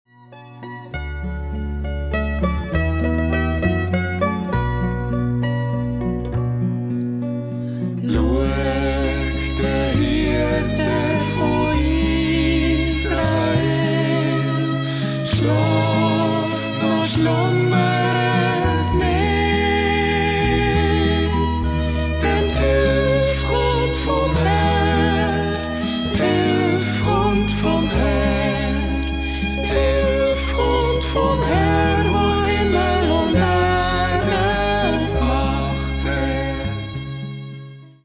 Ihre sanften Lieder in 432Hz
verbreiten eine Atmosphäre von Frieden und Zuversicht